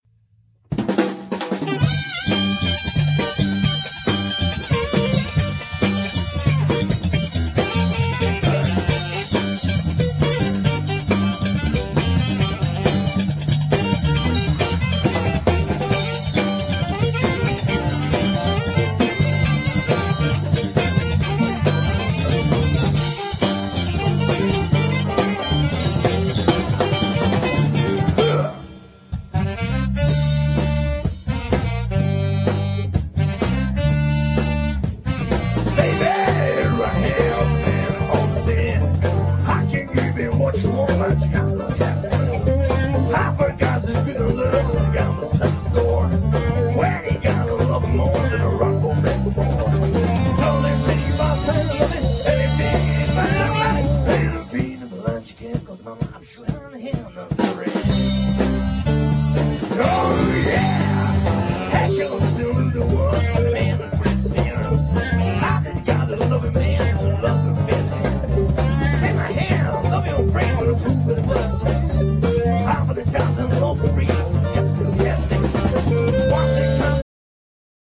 Genere : Rythm and blues / Funk